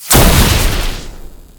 poof.ogg